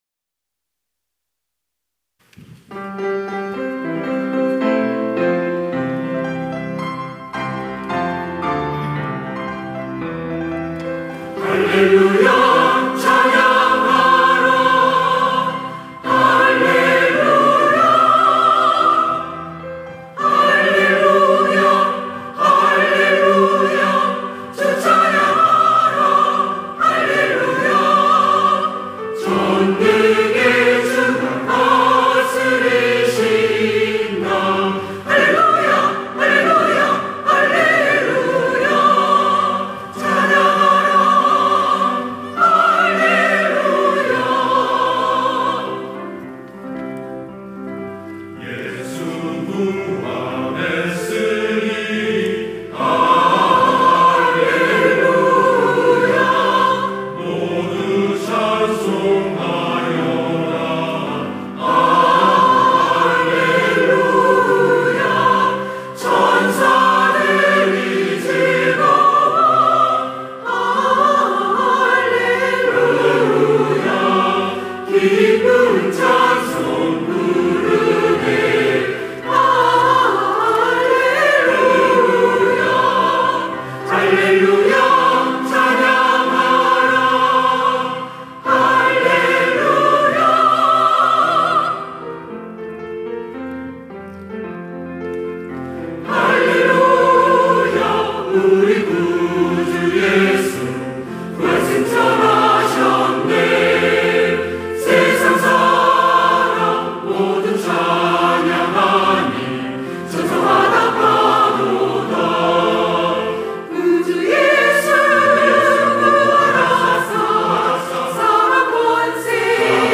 시온(주일1부) - 예수 부활했으니 할렐루야
찬양대 시온